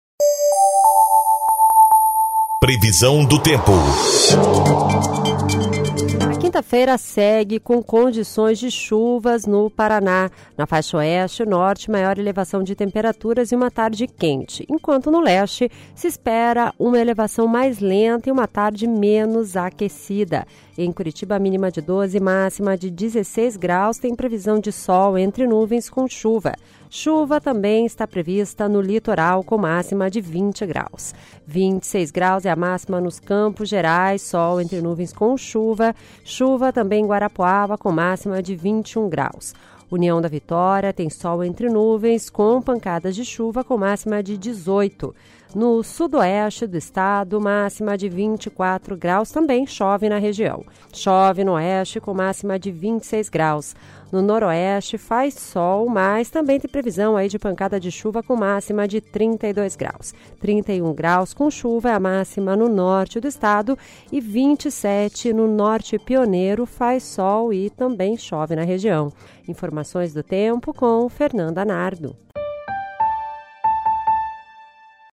Previsão do Tempo (19/10)